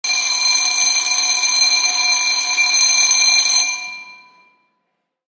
dzwonek_KJC7V61.mp3